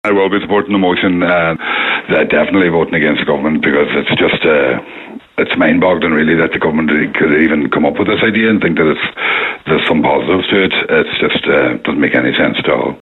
Deputy Pringle says the government’s approach does not make sense…………..